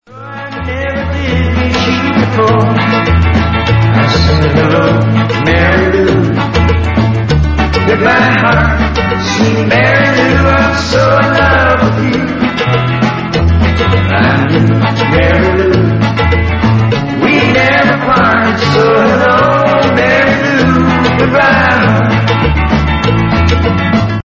He has a good voice, but bad batteries.
Some people dance with him.